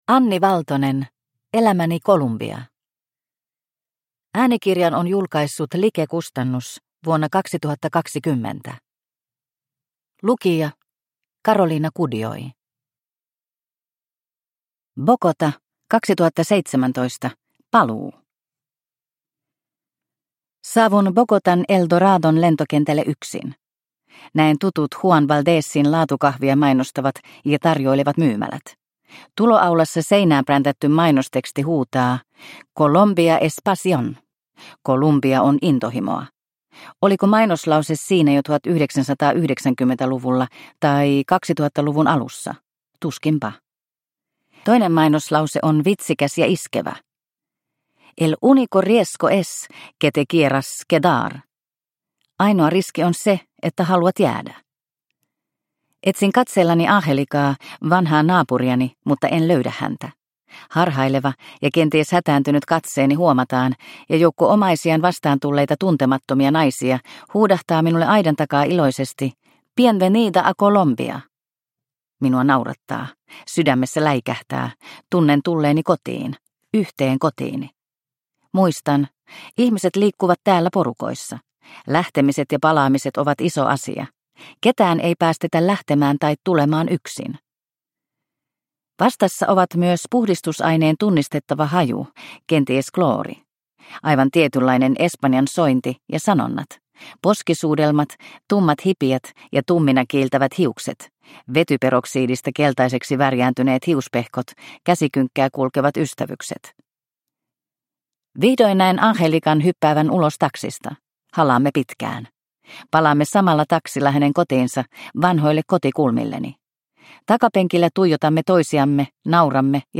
Elämäni Kolumbia – Ljudbok – Laddas ner